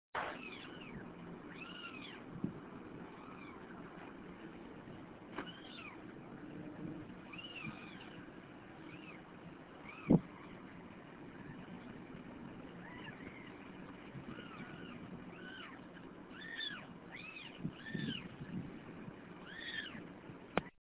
Kittens-Purring.mp3